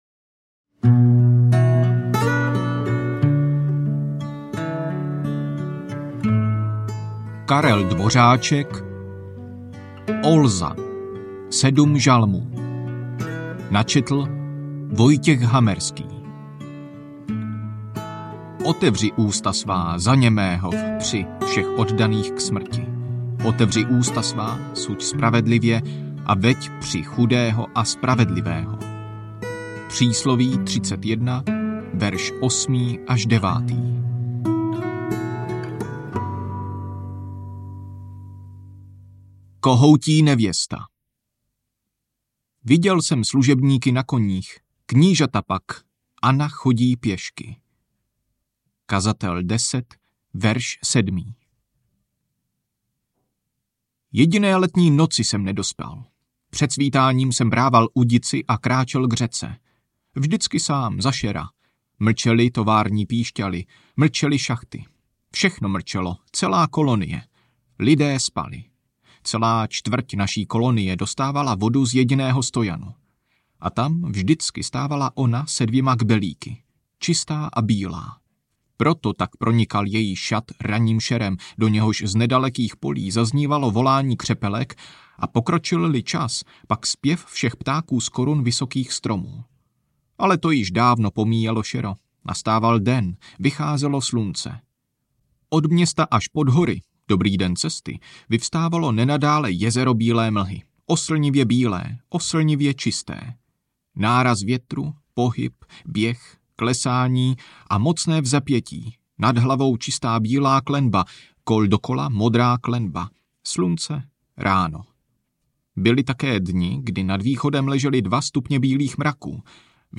Olza audiokniha
Ukázka z knihy